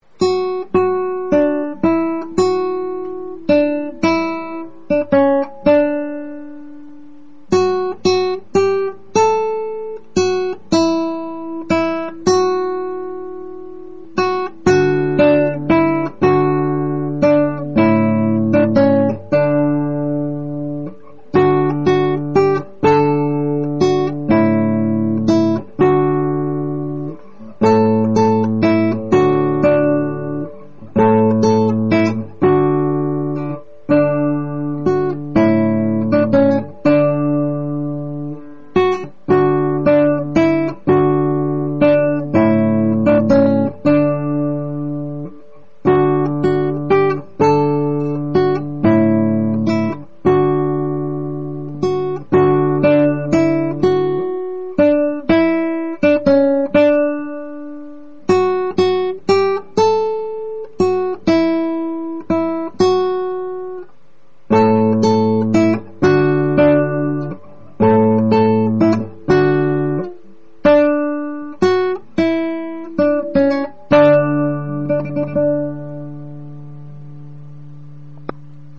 ein Weihnachtslied